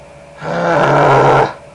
Growl Sound Effect
Download a high-quality growl sound effect.
growl-3.mp3